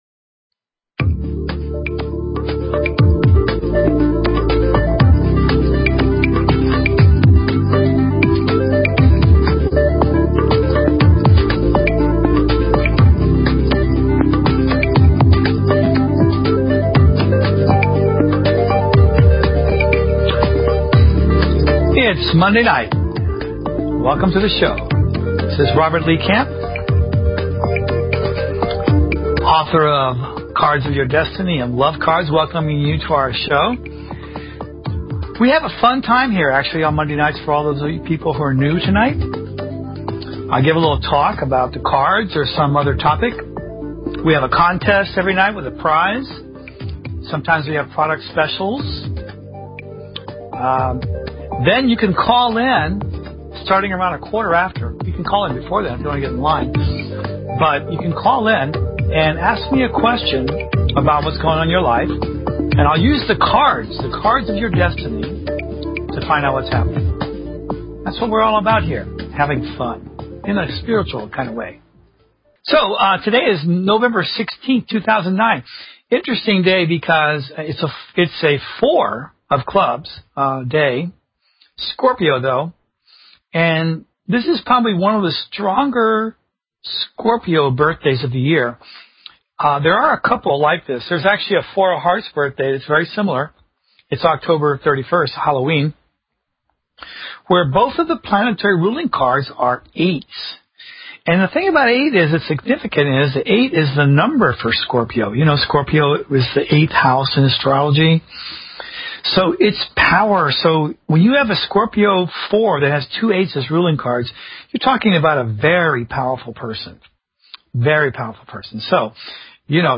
Guest interview or topic discussion.